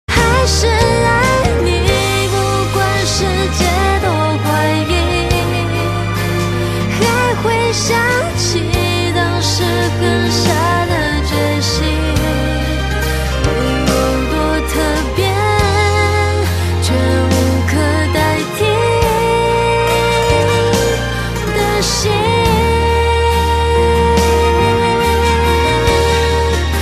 华语歌曲